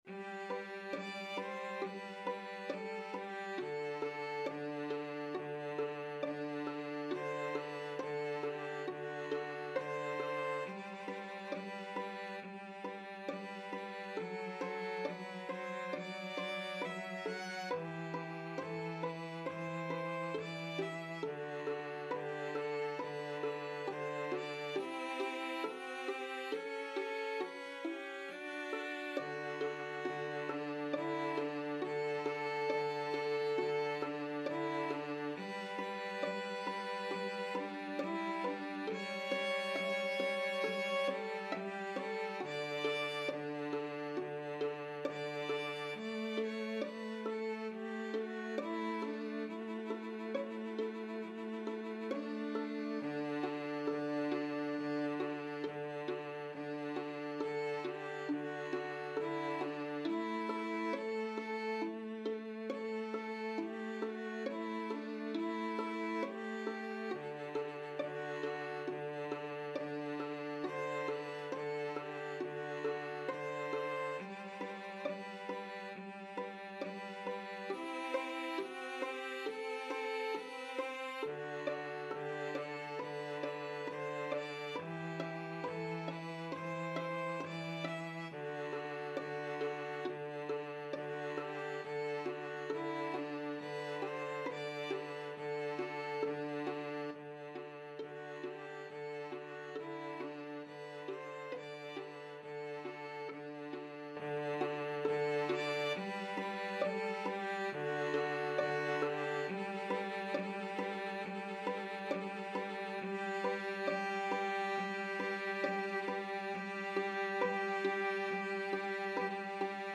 Violin 1Violin 2Cello
G major (Sounding Pitch) (View more G major Music for 2-Violins-Cello )
= 34 Grave
4/4 (View more 4/4 Music)
Classical (View more Classical 2-Violins-Cello Music)